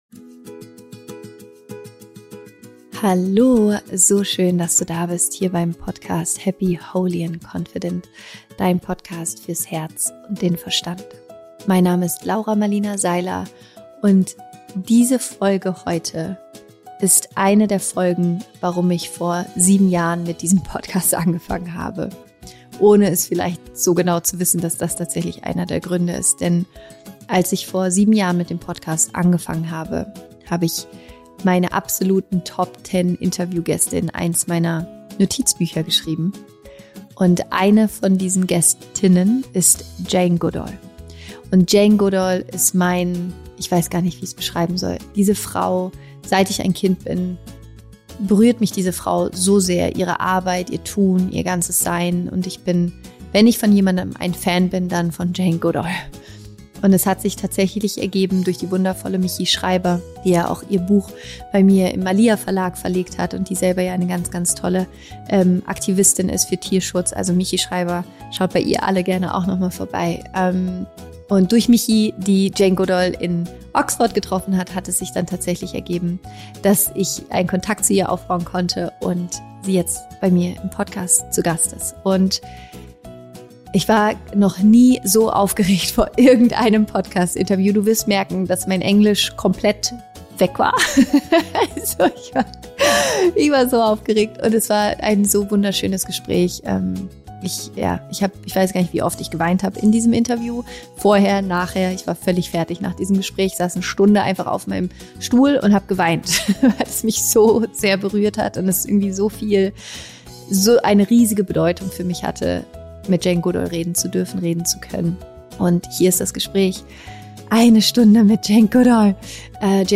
Dieses Interview ist ein Appell an dich, dass auch du etwas zur Veränderung auf dieser Welt beitragen kannst.